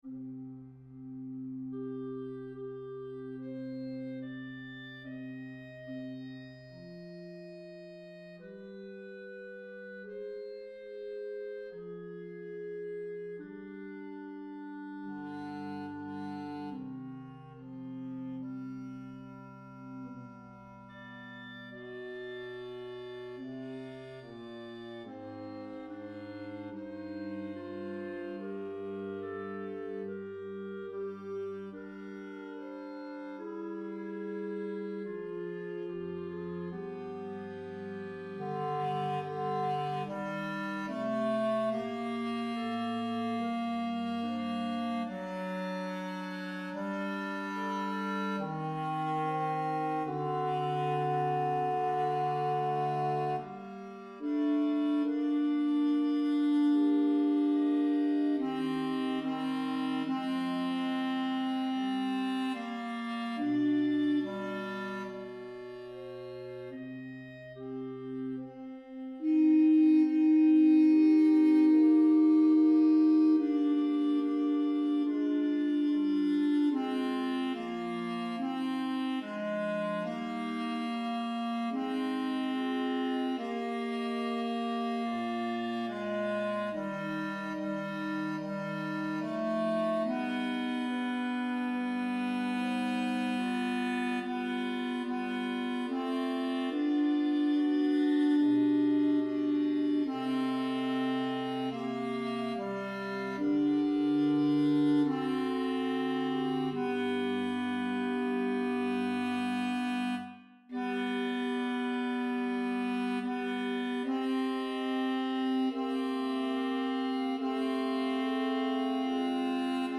In_the_midst_of_life_RR_Tenor.mp3